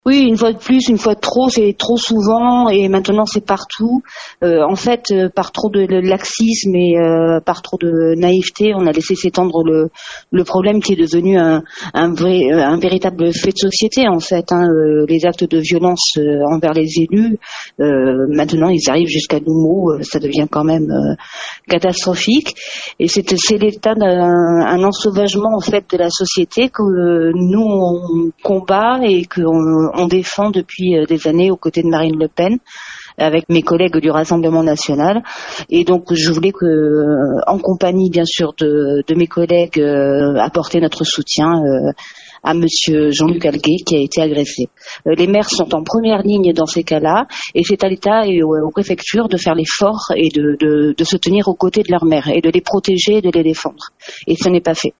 Le parti d’extrême-droite s’est exprimé hier, condamnant fermement ces faits et l’attaque par des gens du voyage. On écoute Séverine Werbrouck, conseillère régionale et déléguée départementale du RN 17, qui dénonce « la fois de trop » :